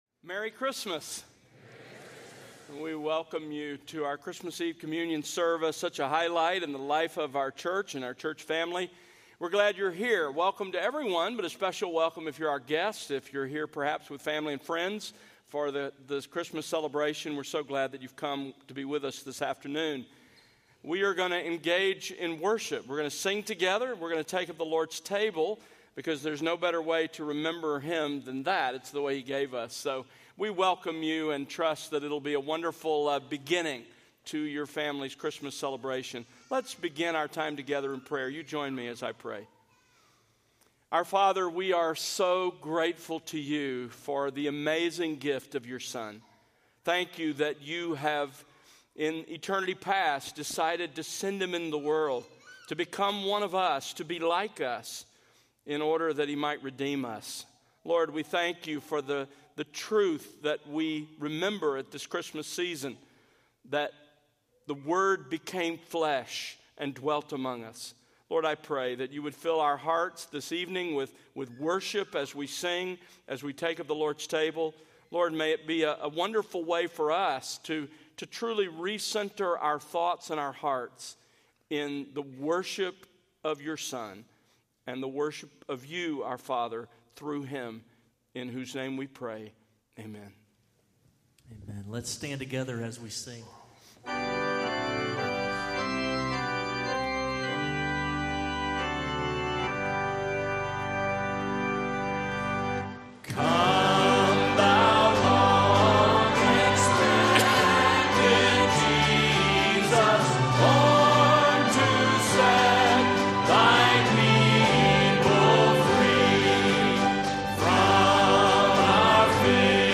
Christmas Eve Communion Service | Countryside Bible Church